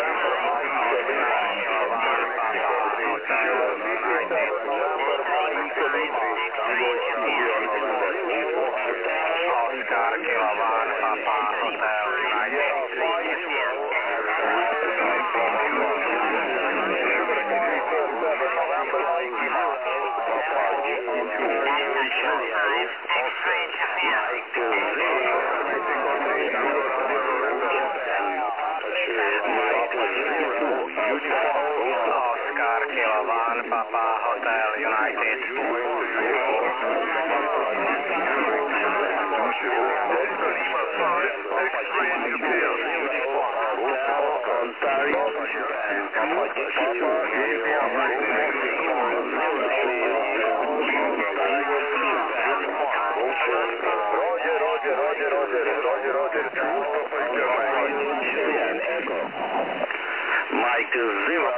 QSO`s via QO-100